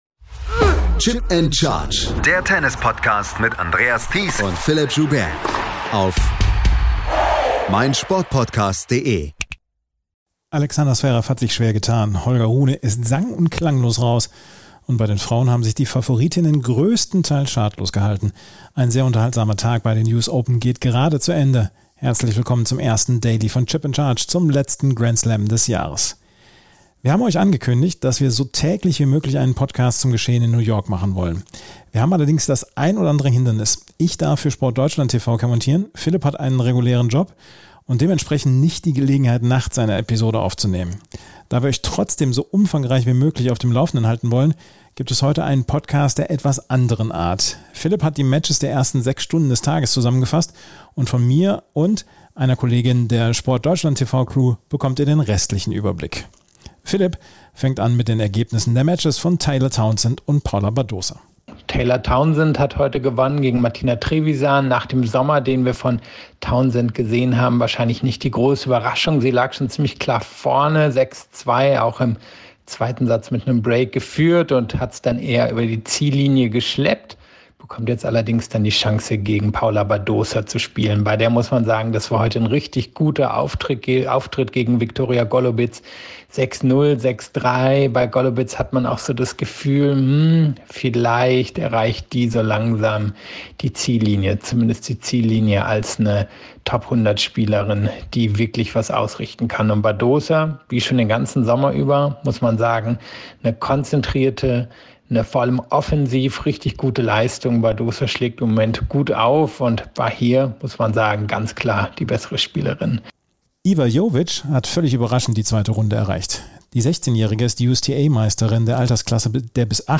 Willkommen zur neuen Ausgabe von Chip & Charge – dieses Mal mit dem ersten Tag der US Open 2024. Dabei hört ihr eine ungewöhnliche Sendung mit insgesamt vier Perspektiven auf das Geschehen am Montag.